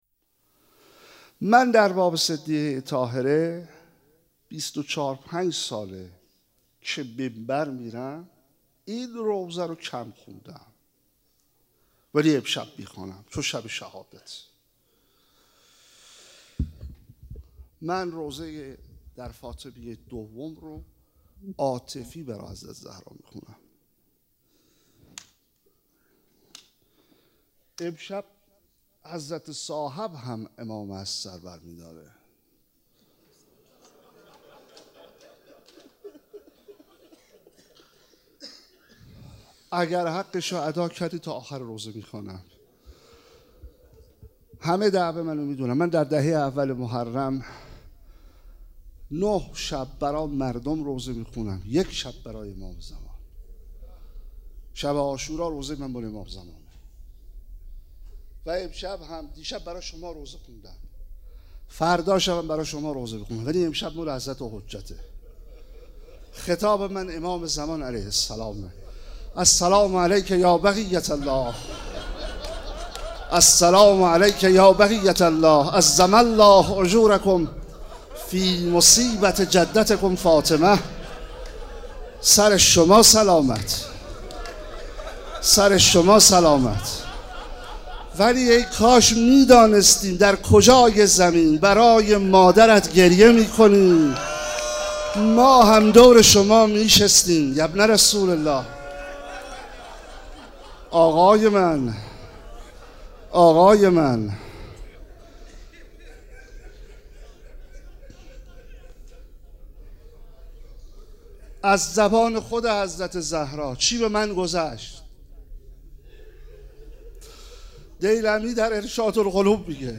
29 دی 97 - مسجد حضرت امیر - روضه حضرت زهرا (س)